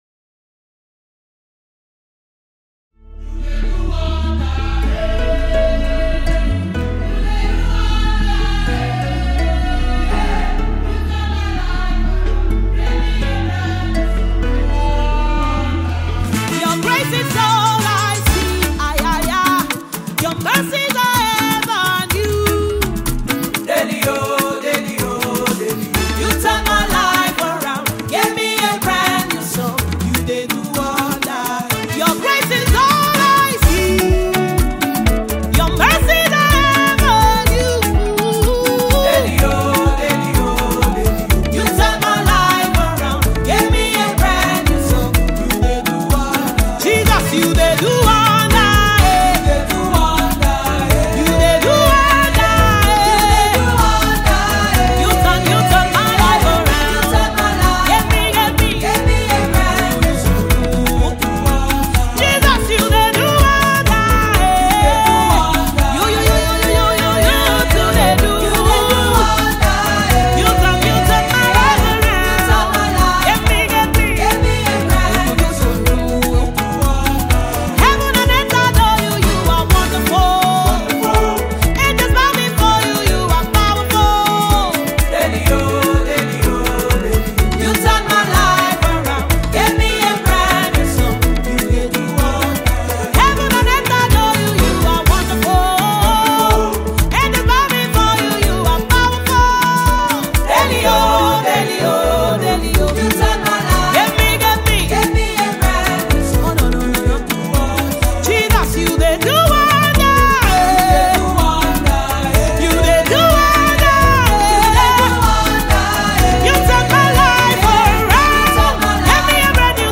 soul-stirring and uplifting music